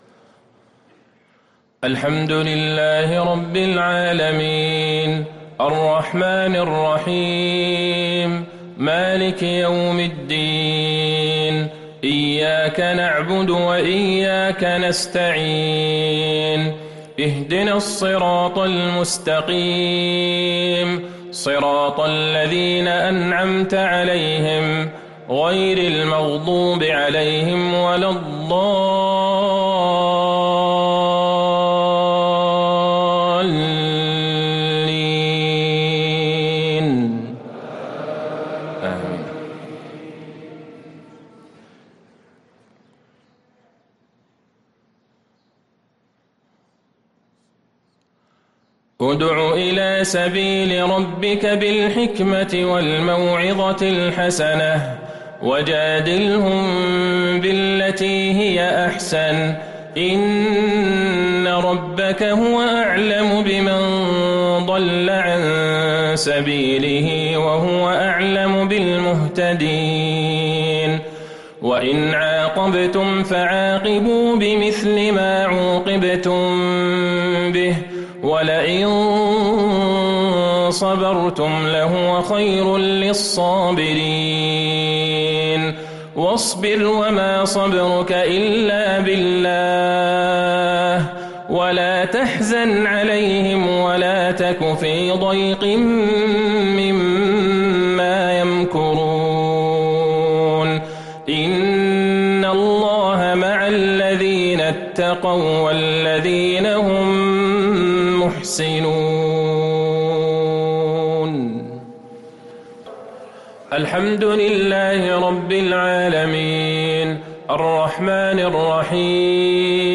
مغرب السبت ٧ صفر ١٤٤٤هـ | خواتيم النحل & الإسراء | Maghrib prayer from Alnahl & Alisraa 3-9-2022 > 1444 🕌 > الفروض - تلاوات الحرمين